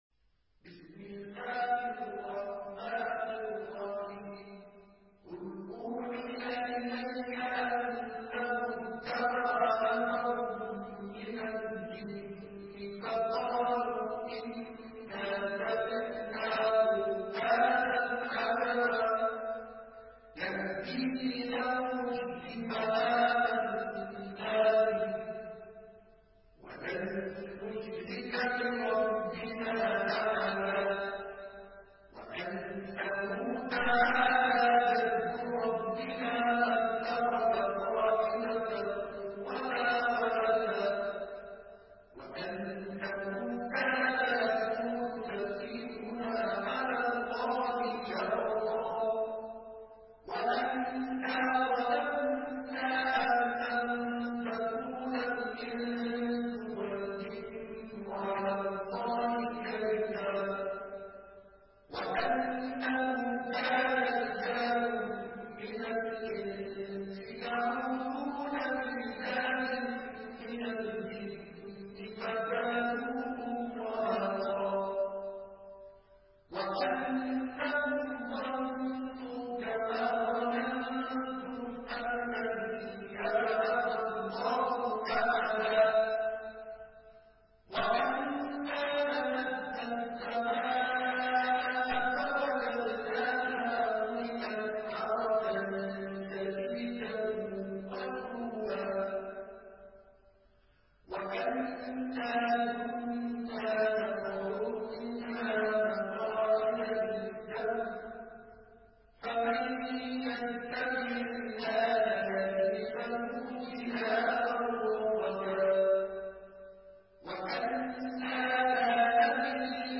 Surah আল-জিন্ন MP3 by Mustafa Ismail in Hafs An Asim narration.
Murattal Hafs An Asim